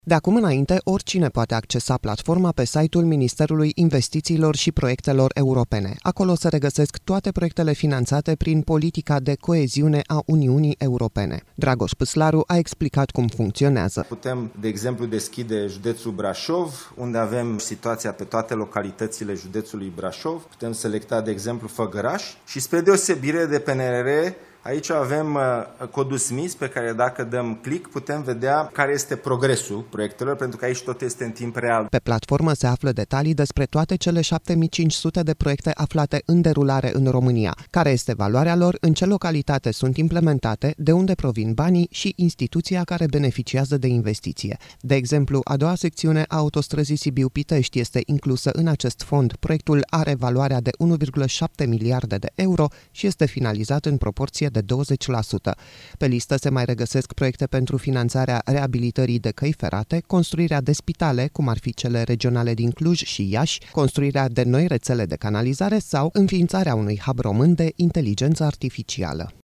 Ministrul Investițiilor și Proiectelor Europene, Dragoș Pîslaru: „Spre deosebire de PNRR, aici avem codul Smys, pe care, dacă dăm click, putem vedea care este progresul proiectelor”
Dragoș Pîslaru a explicat cum funcționează.